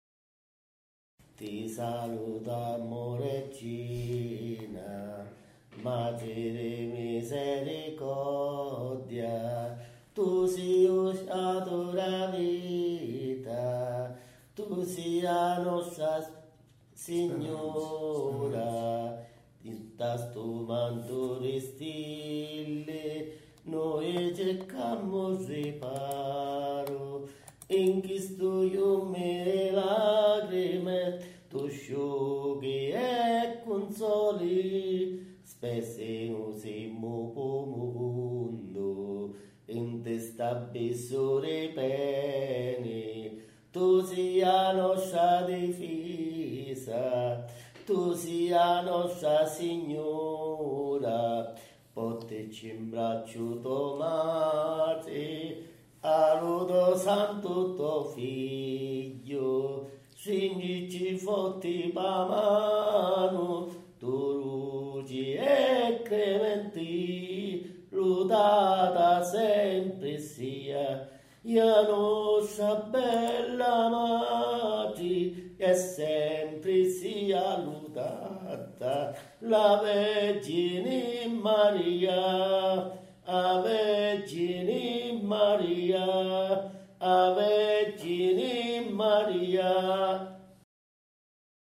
PREGHIERE E CANTI RELIGIOSI